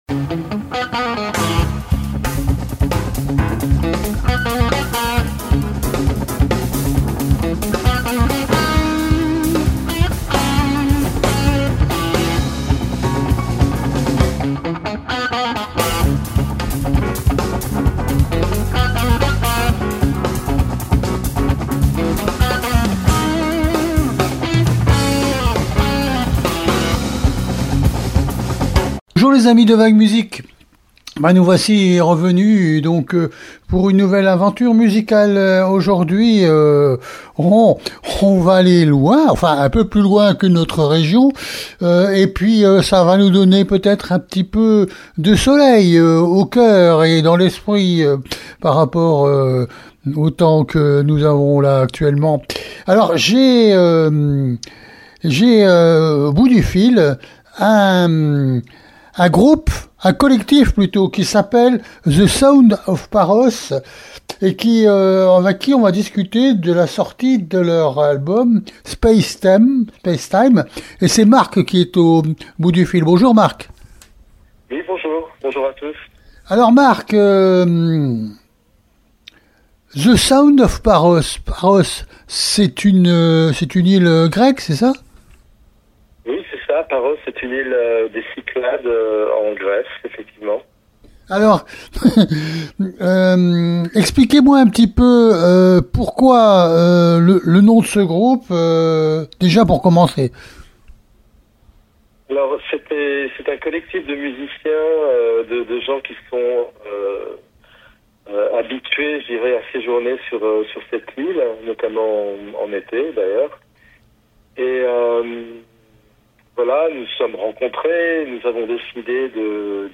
THE SOUND OF PAROS -VAG MUSIC interview du 24 novembre 2025